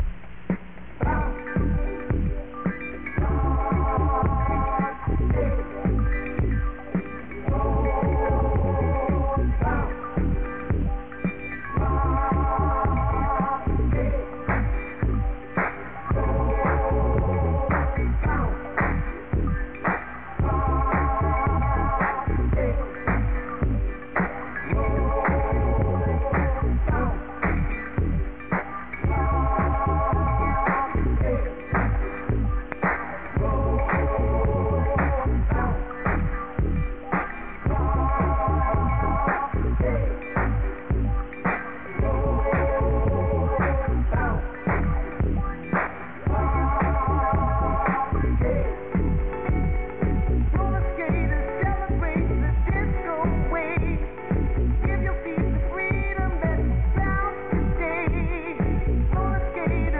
70's/DISCO